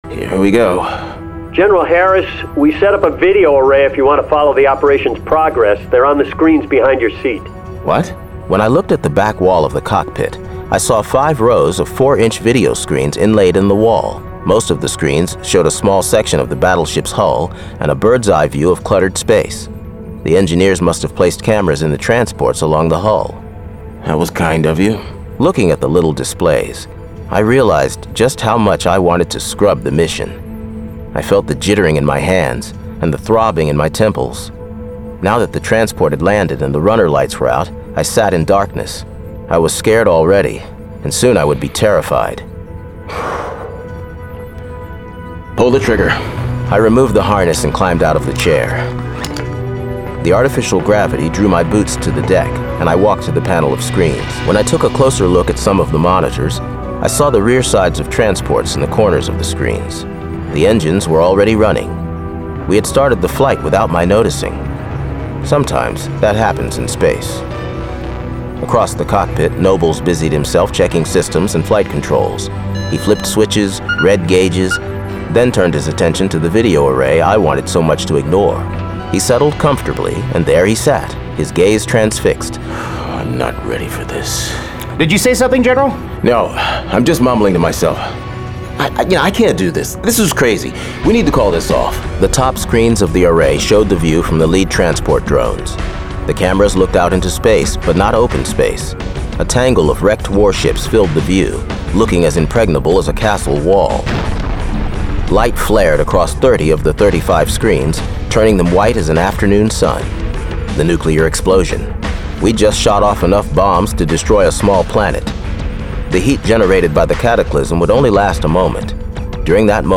Full Cast. Cinematic Music. Sound Effects.
[Dramatized Adaptation]
Genre: Science Fiction